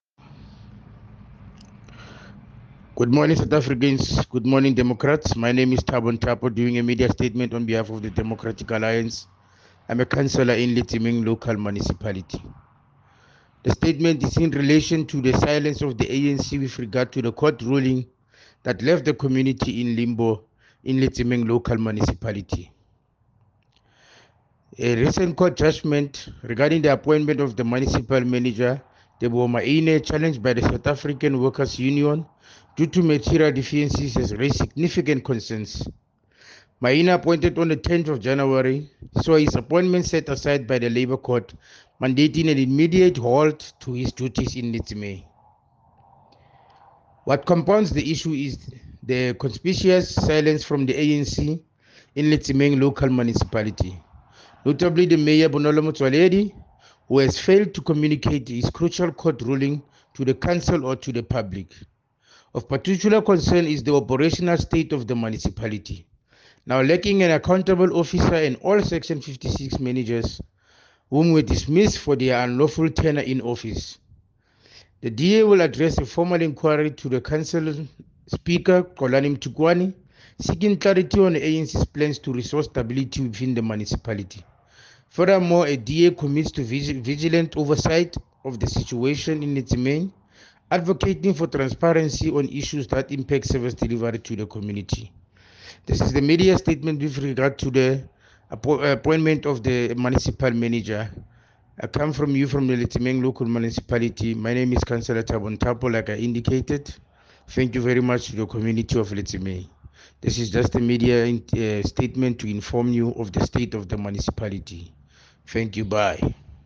Issued by Thabo Nthapo – DA Councillor Letsemeng Local Municipality
English soundbite by Cllr Thabo Nthapo and